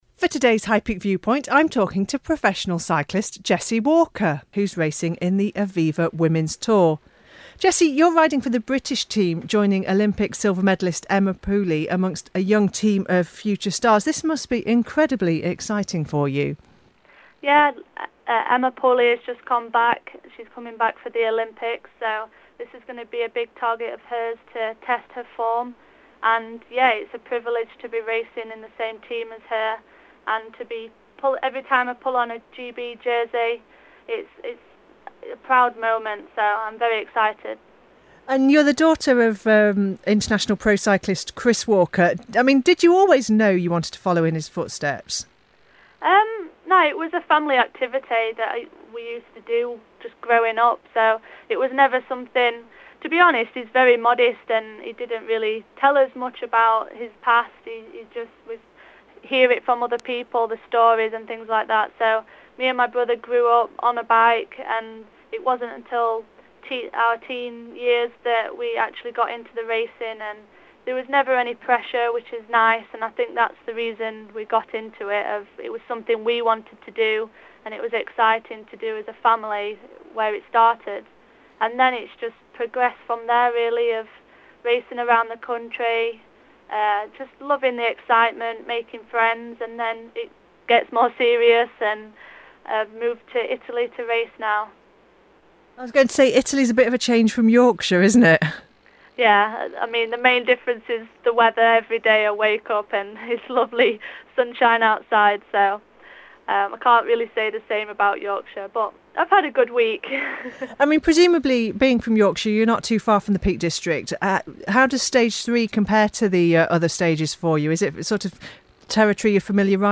Stage 3 of the Aviva Women’s Tour heads from Ashbourne via Buxton, Baslow and Matlock over to the finish in Chesterfield (Friday 17th June). Talking to High Peak and Ashbourne Radio before the race